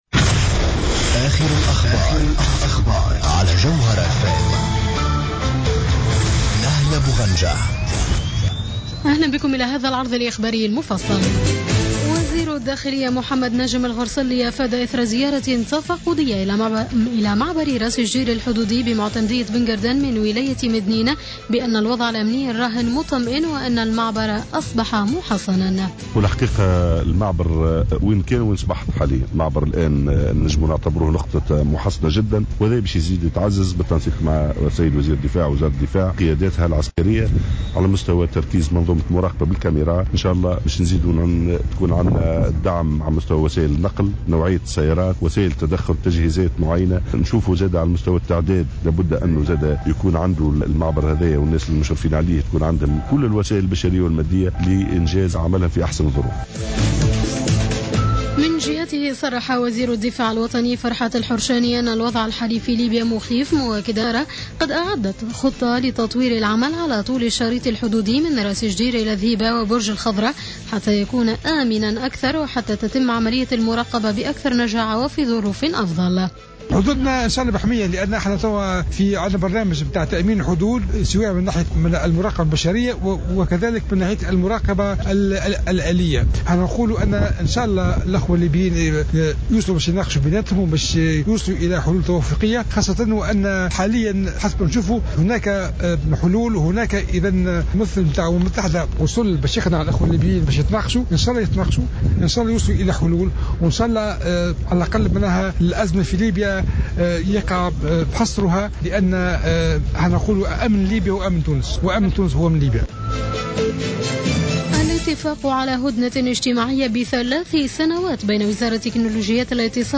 نشرة الأخبار منتصف الليل ليوم الأحد 08 مارس 2015